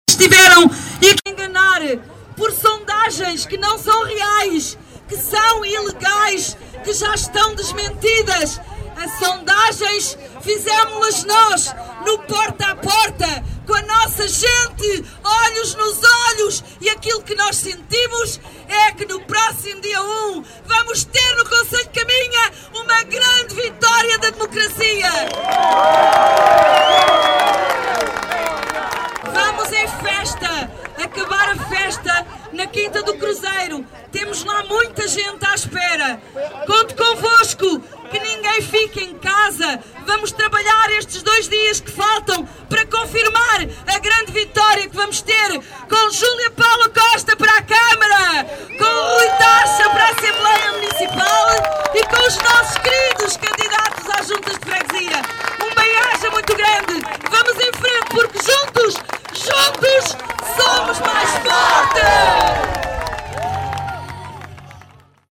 A candidata do PSD à Câmara de Caminha escolheu o largo da Feira da sede do concelho para iniciar a caravana que a levou até à Quinta do Cruzeiro em Vile onde decorre esta noite um jantar comício de encerramento de campanha.
Antes de partir na caravana, Júlia Paula Costa dirigiu-se aos apoiantes para lhes dizer que não se deixem embarcar em sondagens que não são reais, que são ilegais e já foram desmentidas.